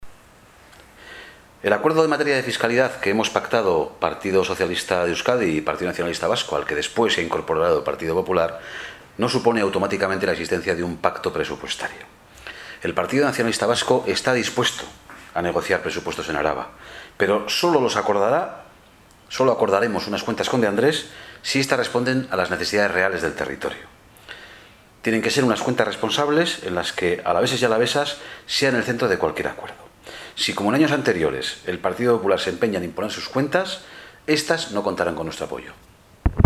Vitoria-Gasteiz, 15 de octubre de 2013 Esta mañana, Ramiro González, portavoz del Grupo Juntero EAJ-PNV, ha comparecido ante los medios de comunicación, con el objeto de trasladar la posición de su partido respecto a los Presupuestos Forales para 2014.